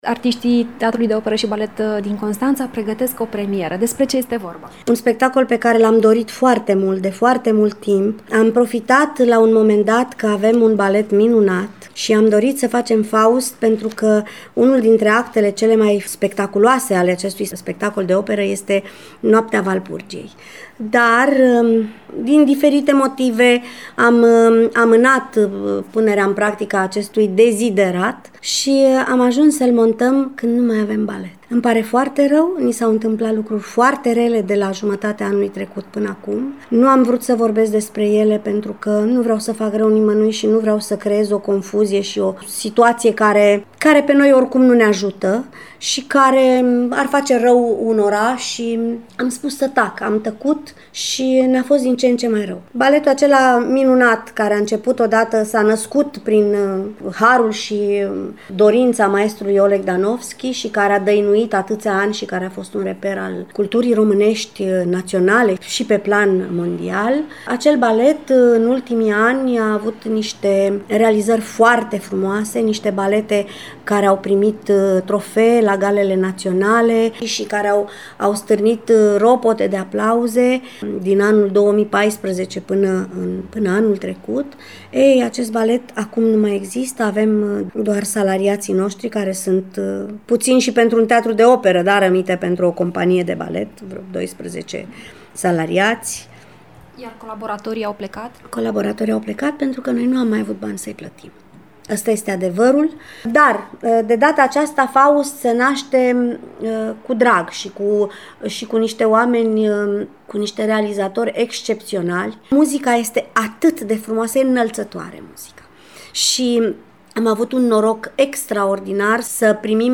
Într-un interviu acordat colegei noastre